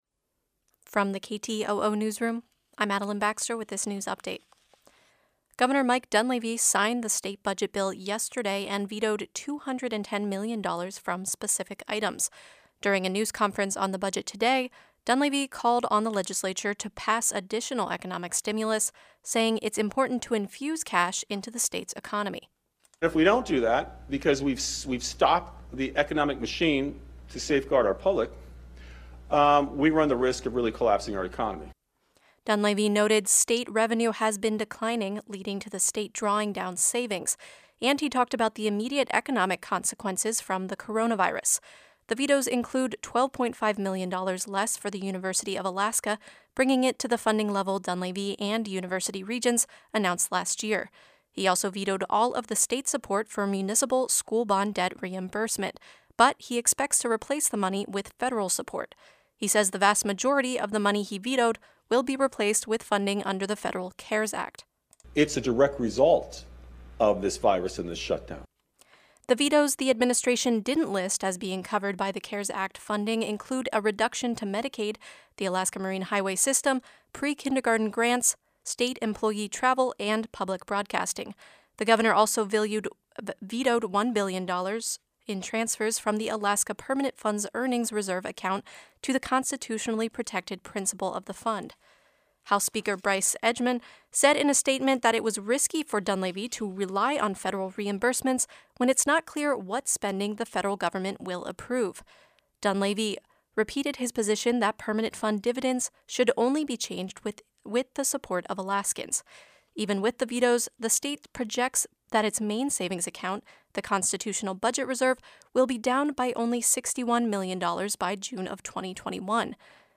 Newscast — Tuesday, April 7, 2020